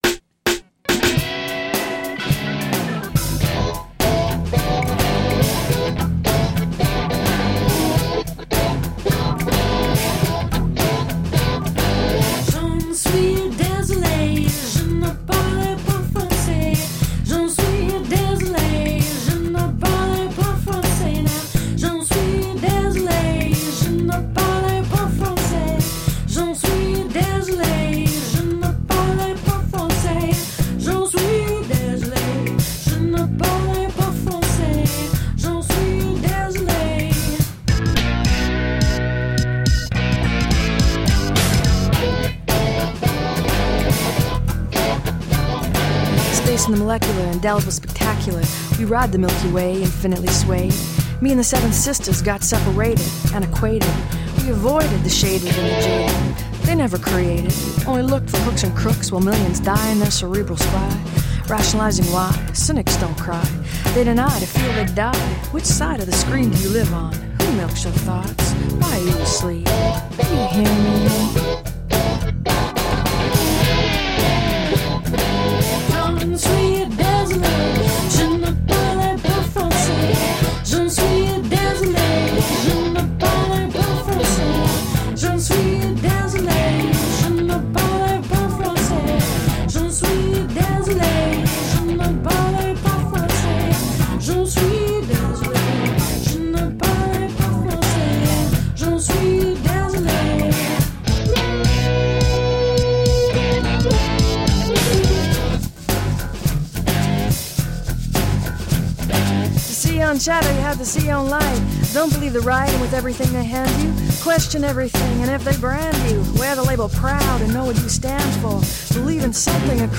Jazzed up, funk-tinged eclectic pop..